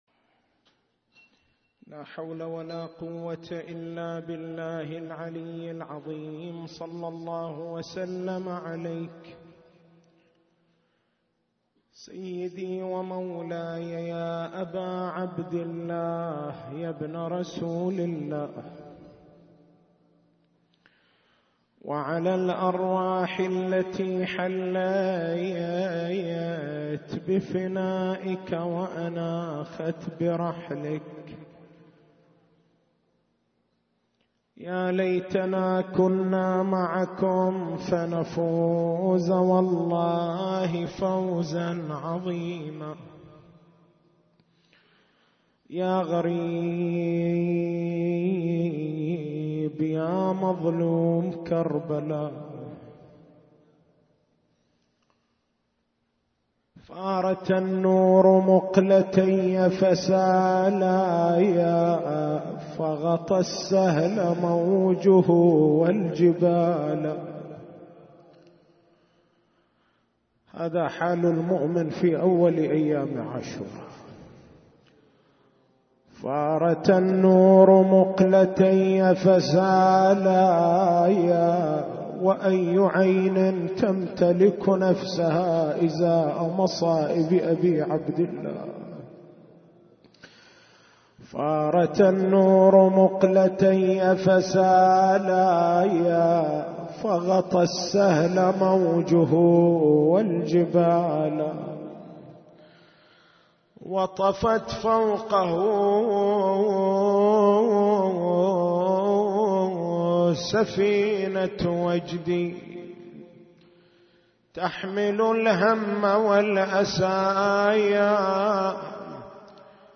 تاريخ المحاضرة
حسينية الزين بالقديح